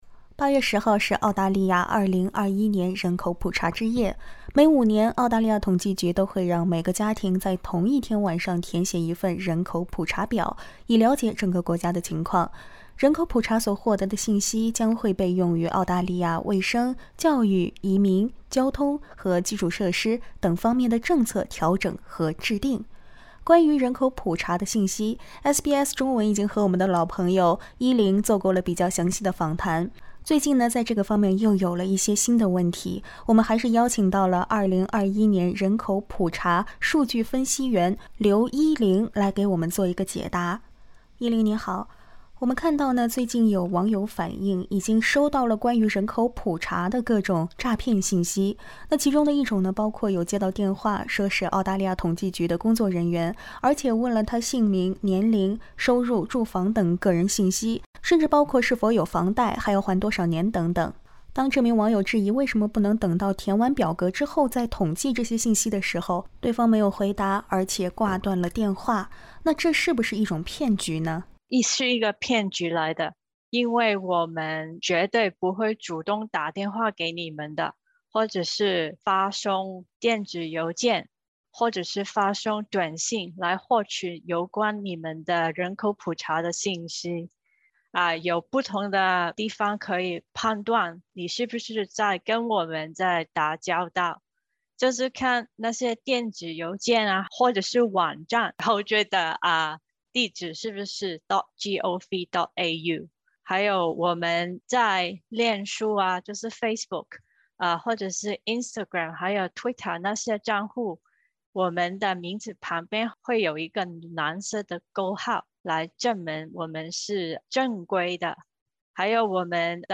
澳大利亚统计局绝对不会通过电话、邮件、短信、社交媒体，向您询问银行账户、信用卡、身份证件、税号等信息。（请点击图片收听采访）